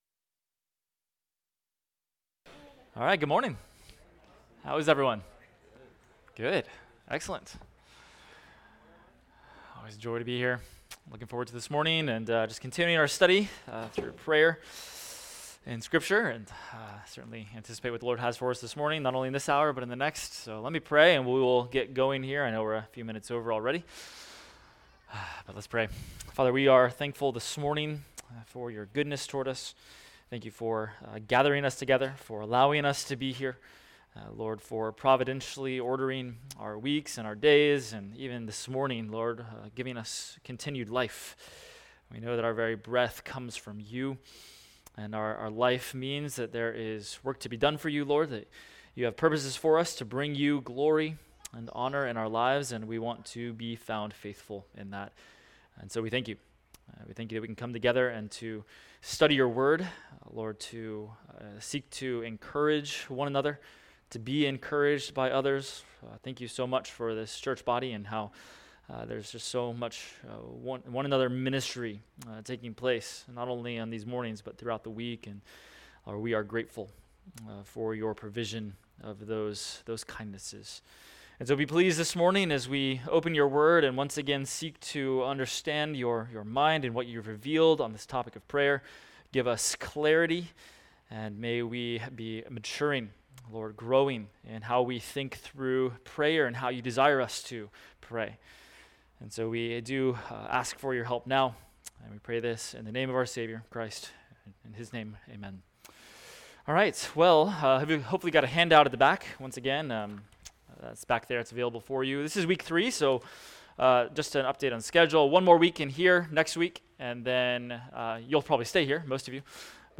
Adult Sunday School – Prayers of Scripture – Week 3